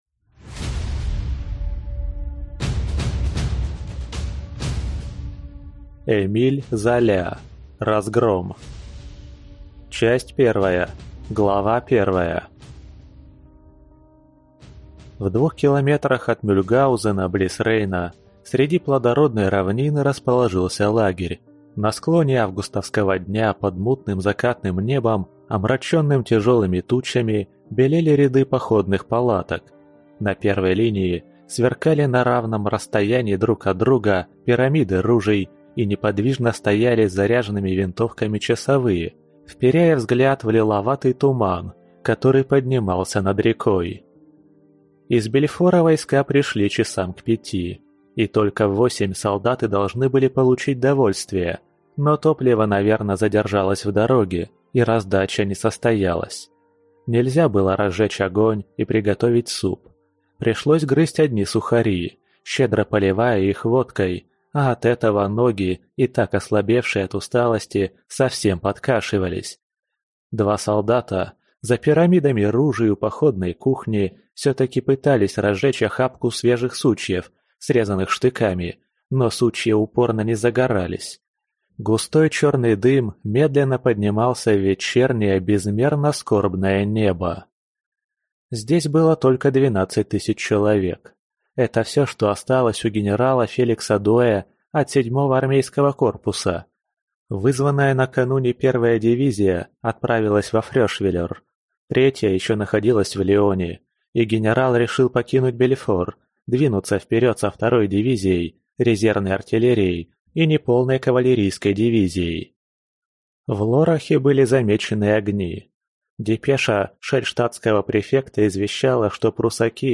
Аудиокнига Разгром | Библиотека аудиокниг
Прослушать и бесплатно скачать фрагмент аудиокниги